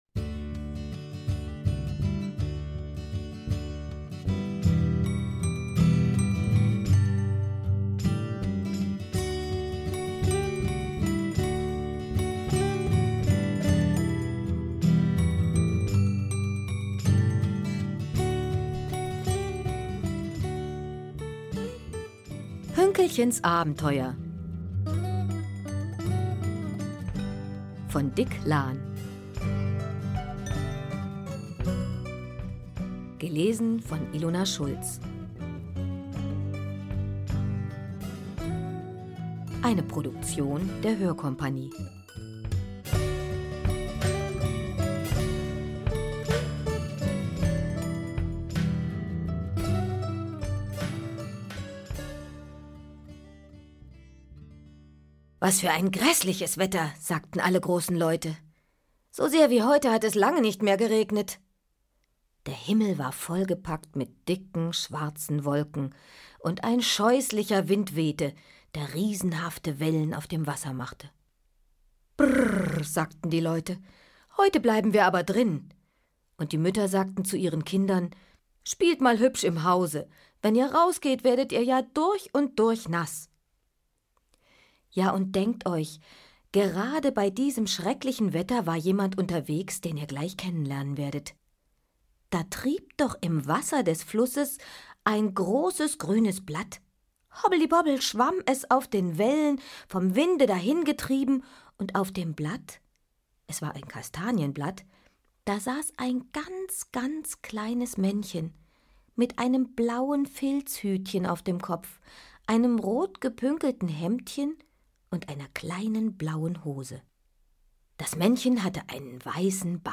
Schlagworte Hörbuch • Kinder • Kinder-CDs (Audio) • Lesung • Spaß • Witz